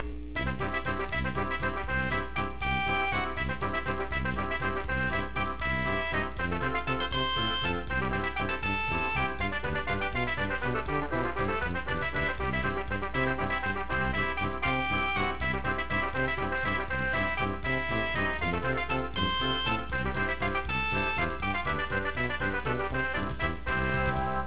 A little polka from my Midwestern roots!!
polka.ra